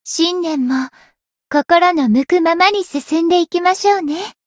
灵魂潮汐-薇姬娜-春节（摸头语音）.ogg